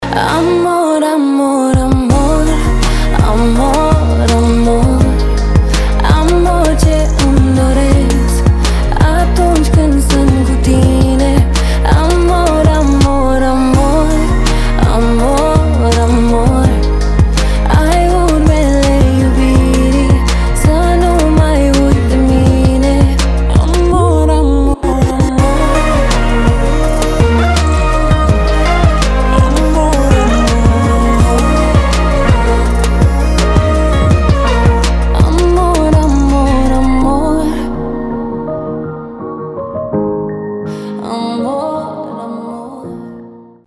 Романтические рингтоны
Спокойные рингтоны , Нежные рингтоны